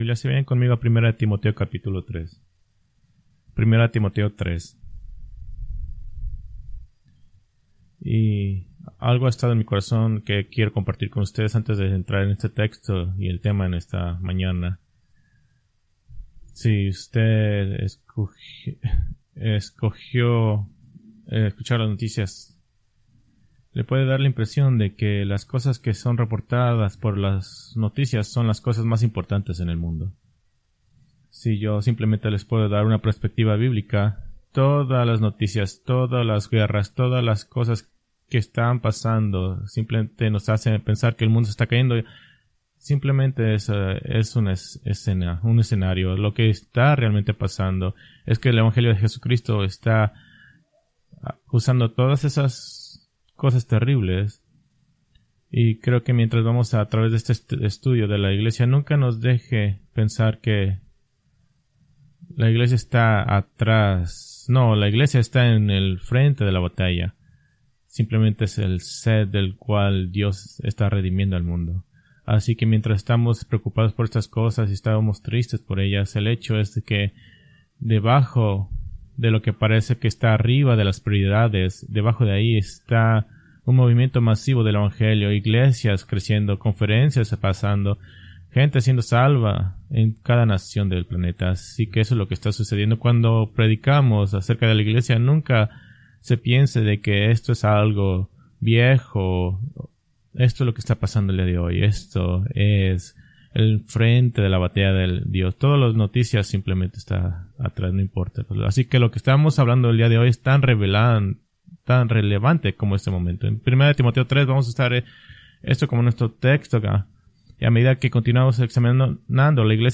Preached May 5, 2024 from Escrituras seleccionadas